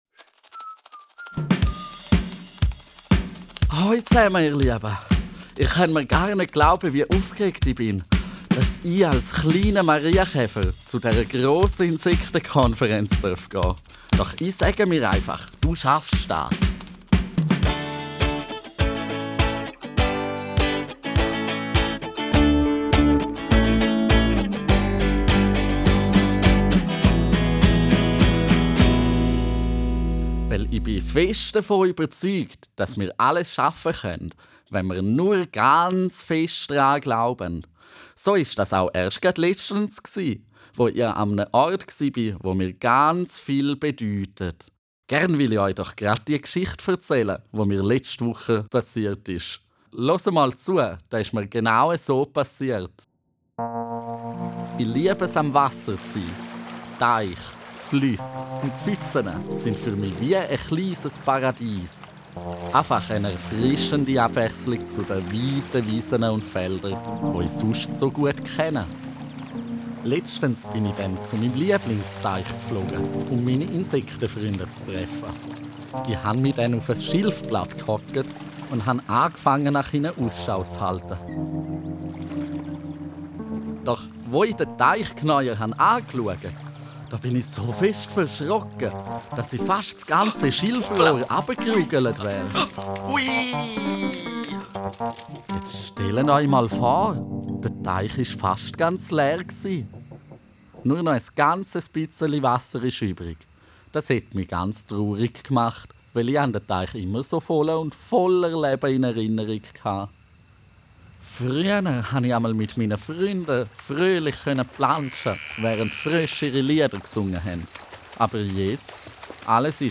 Startet mit dem Hörspiel: Hört das Hörspiel über eure Lautsprecher.